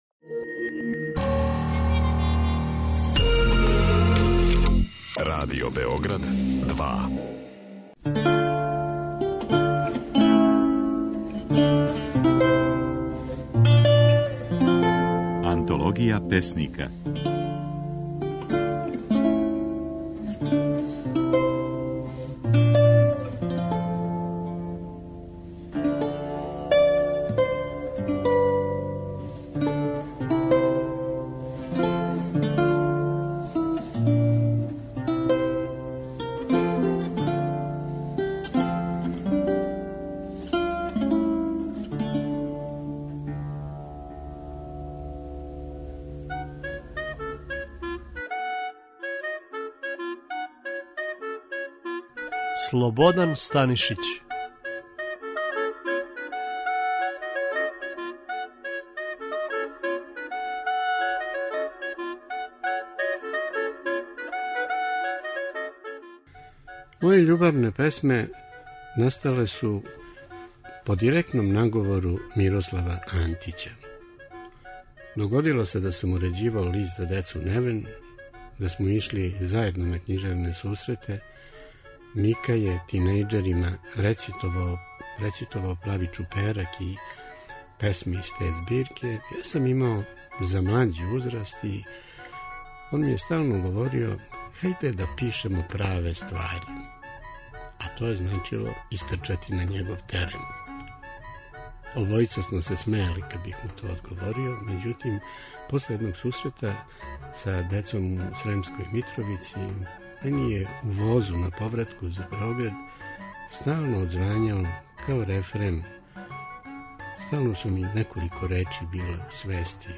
Oд 20. до 24. марта, можете чути како своје стихове говори песник Слободан Станишић (1939).